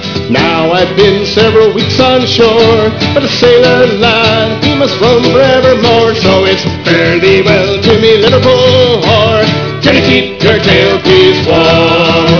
vocals, violin
vocals, bodhran
-- vocals, guitar, pennywhistle
vocals, octave mandolin, hammered dulcimer